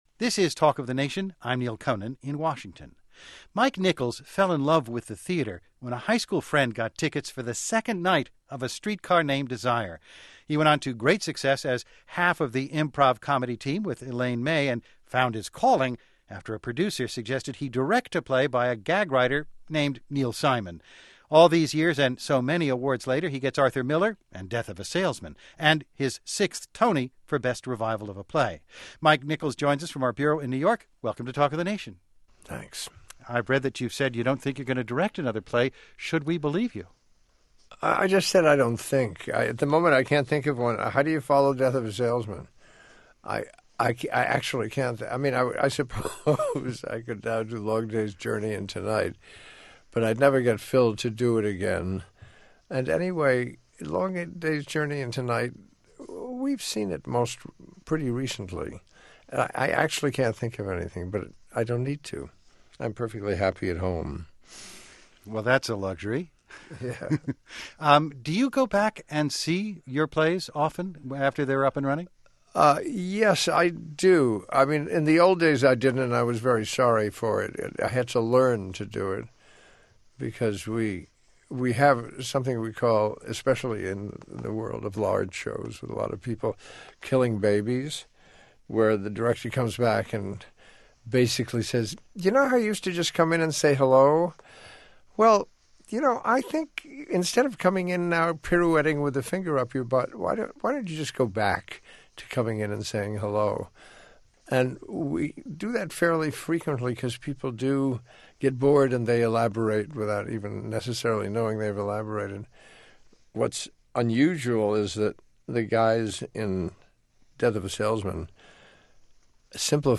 As a reminder of Mike Nichols and his indelible mark on our culture, I ran across this 2012 interview conducted by Neal Conan of NPR for the Talk Of The Nation series on July 4th 2012.